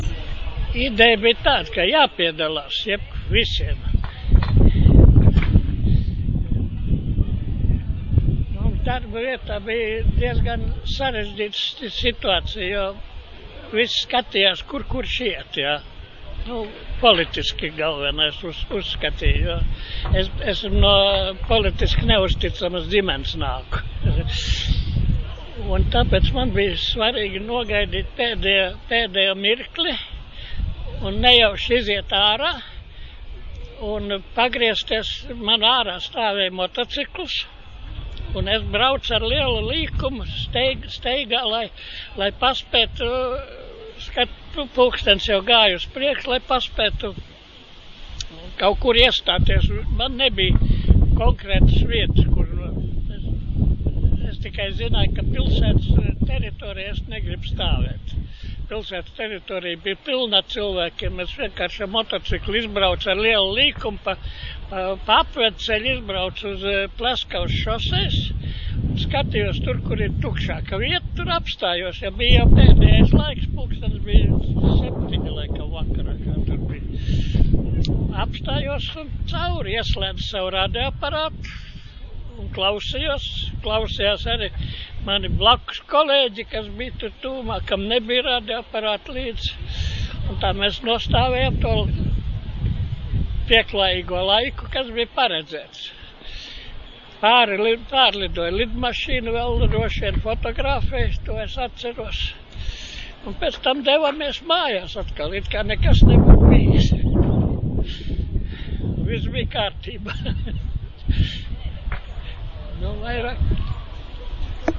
Atmiņu stāsts ierakstīts Eiropas digitālās bibliotēkas "Europeana" un Latvijas Nacionālās bibliotēkas organizētajās Baltijas ceļa atceres dienās, kas notika 2013. gada 23. un 24. augustā Rīgā, Esplanādē.